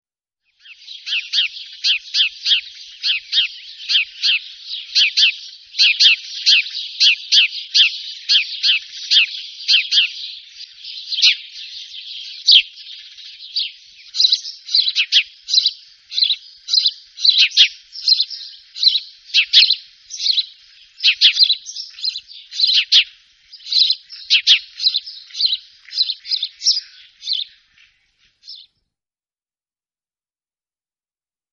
die genannten und abgebildeten Vögel sind im Park anzutreffen
Haussperling
Haussperling.MP3